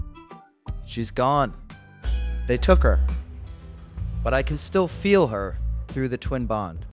novafarma/assets/audio 🔴/voiceover/prologue/prologue_14.wav